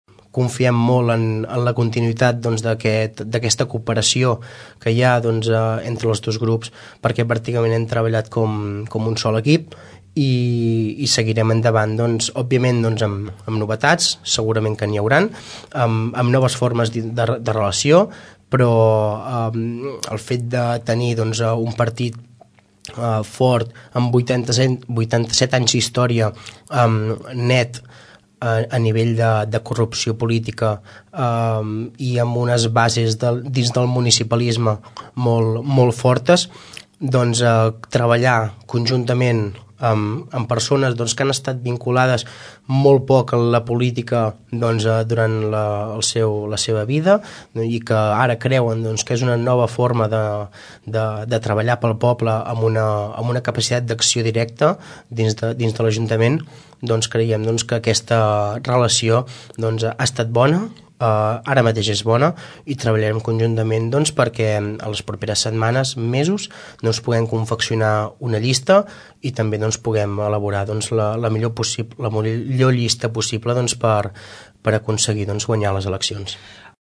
El regidor d’ERC Jordi Romaguera explica la importància de la cooperació amb Gent de Tordera.